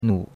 nu3.mp3